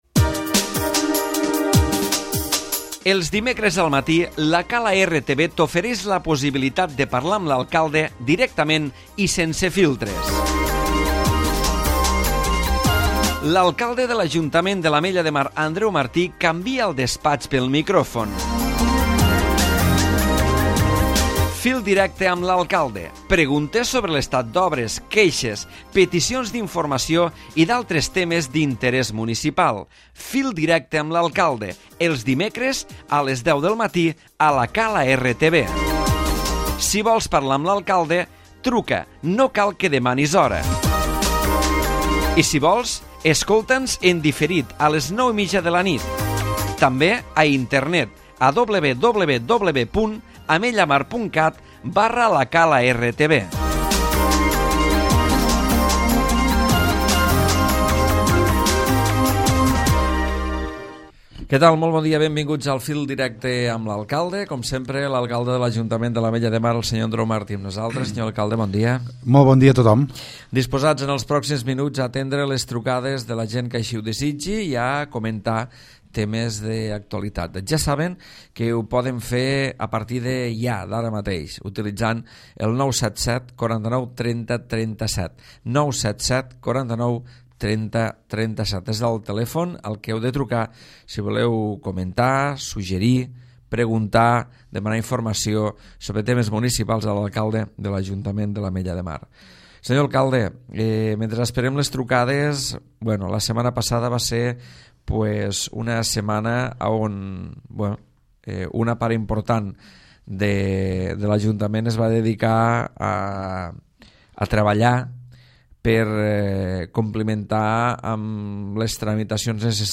L'Alcalde Andreu Martí, ha parlat avui al fil directe de l'avaluació dels danys causats pels aiguats del 17 de setembre.